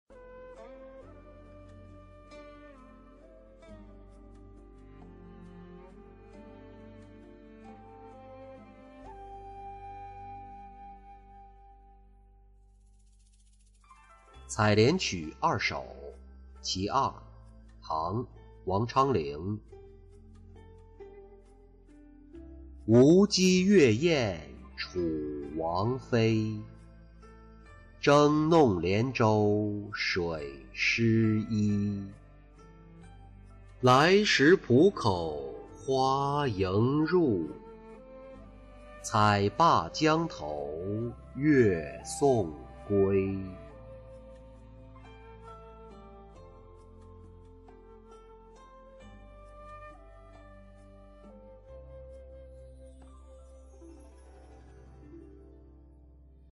采莲曲二首·其一-音频朗读